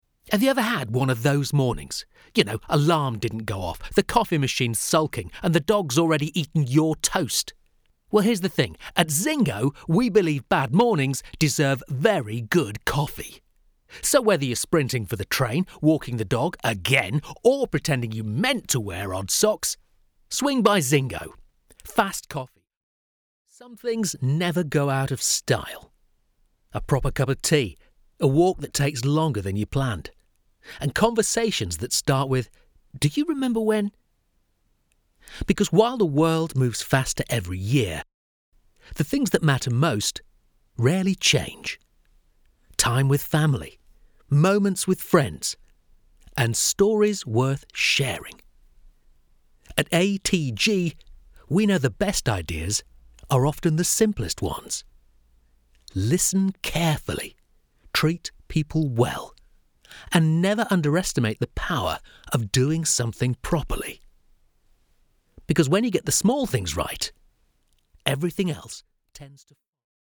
Corporate narration for audio and video
Clear, natural voice for documentaries and corporate